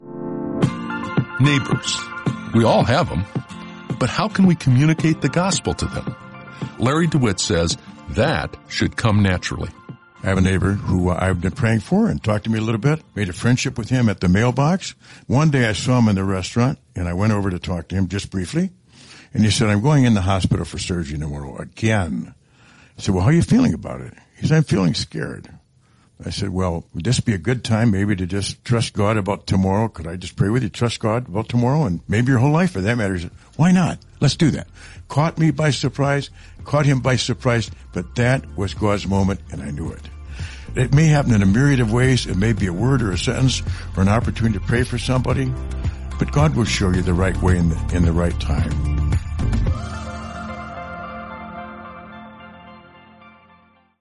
Each day, you’ll hear a short audio message with simple ideas to help you grow in your faith. The messages include real stories, personal thoughts, and tips you can use right away.